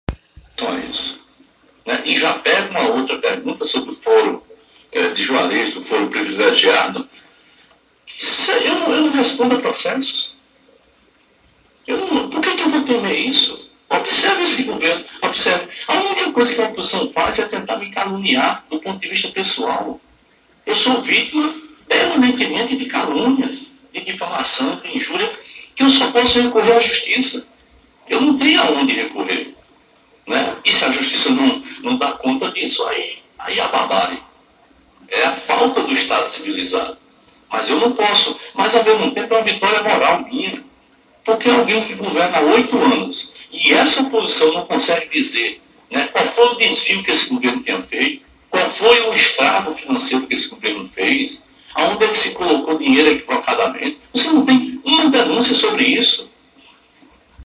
Foi o fato de que, há bem poucos dias, o governador afirmou, em entrevista à TV Master, que não respondia a nenhum processo.
Durante a entrevista, concedida em 16 de abril, o governador foi questionado se temia perder o foro privilegiado, ao deixar o mandato.